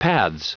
Prononciation du mot paths en anglais (fichier audio)
Prononciation du mot : paths